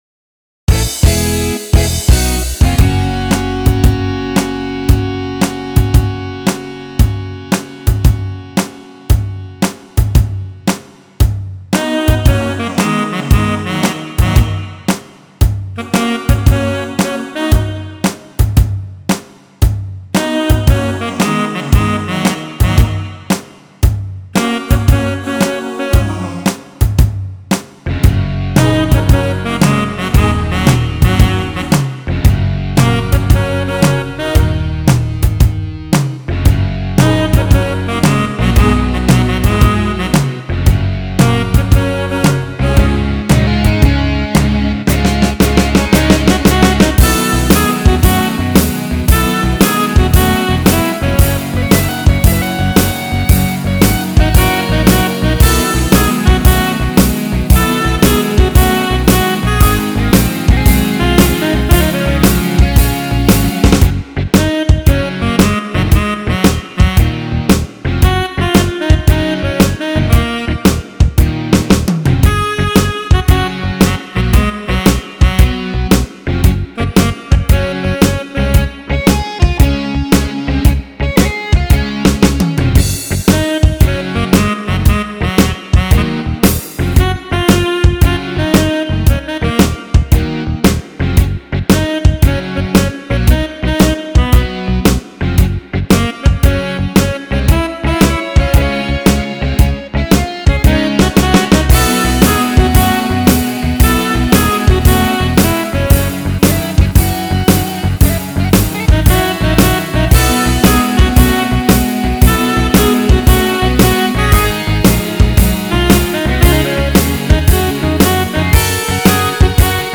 rock classic